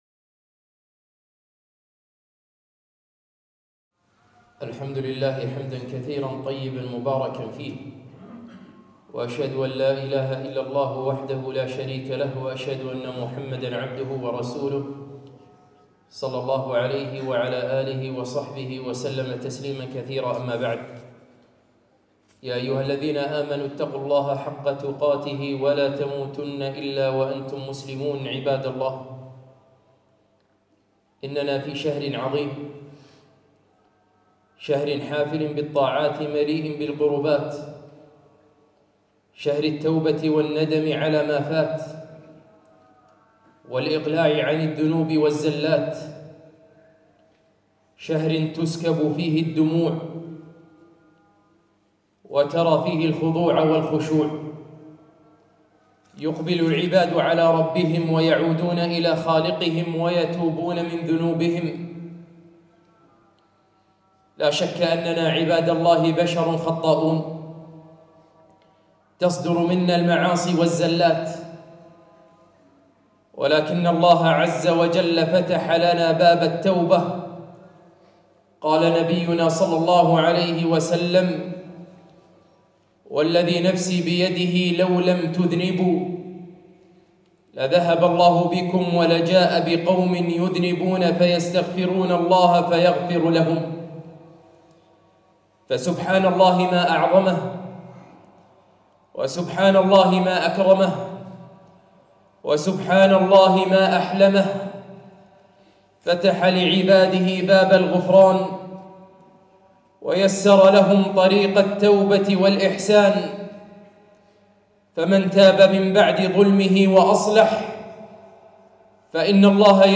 خطبة - شهر التوبة